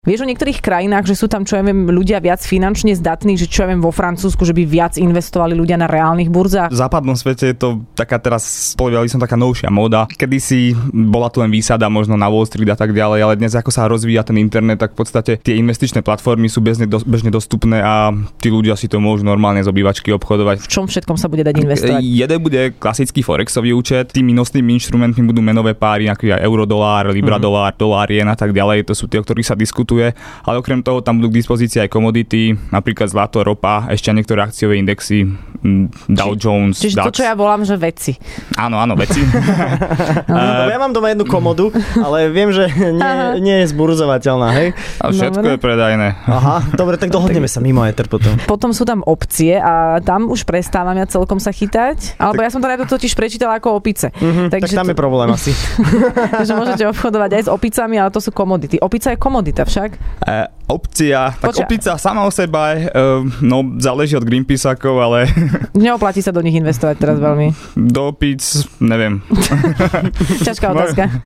Ranná šou